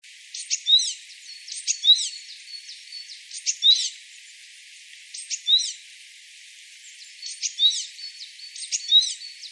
[シジュウカラ]
体が小さいと声も高くなるのでしょうか。